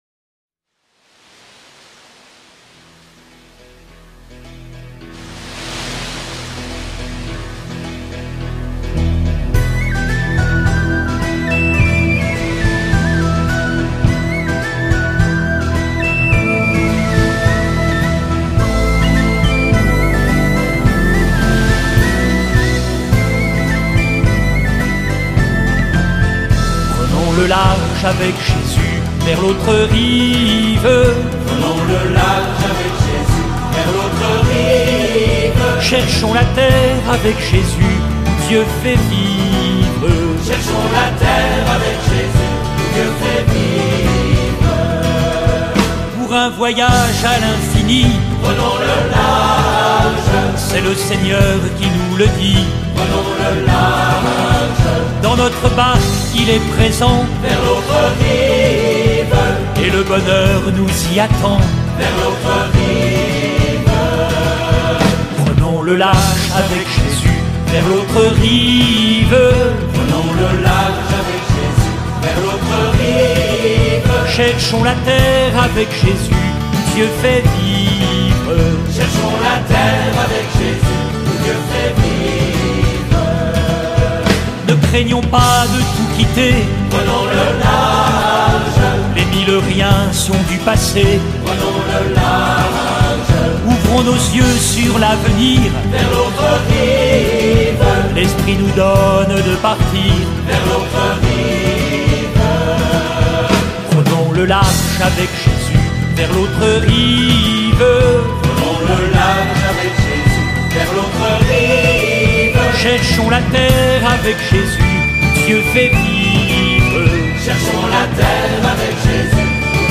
Voici le chant « Prenons le large » si vous voulez le reprendre en famille, il sera le fil rouge de l’année pour nos temps forts.